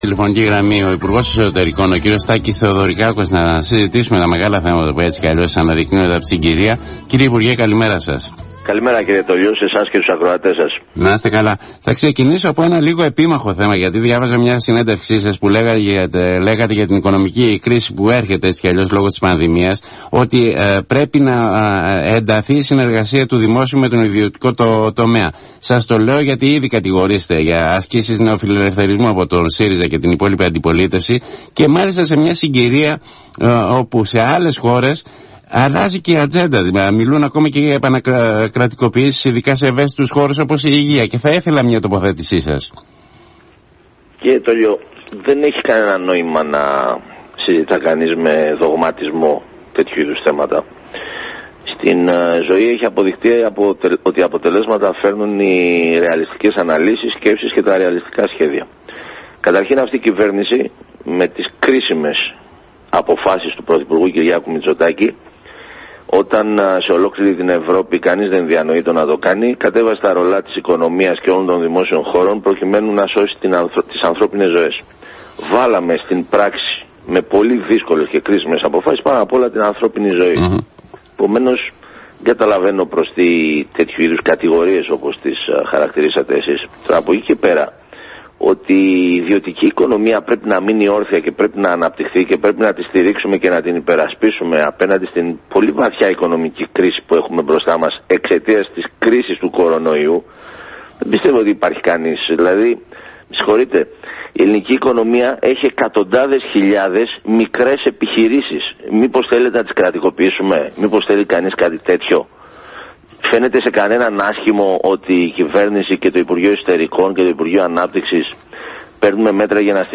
Στην παραδοχή ότι το 2020 θα είναι πολύ δύσκολη χρονιά για τη χώρα, προχώρησε ο υπουργός Εσωτερικών Τάκης Θεοδωρικάκος.